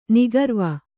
♪ nigarva